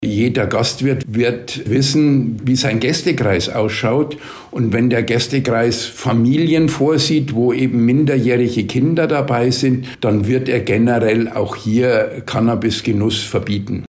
Interview: Cannabis-Teillegalisierung - das sagt die DEHOGA - PRIMATON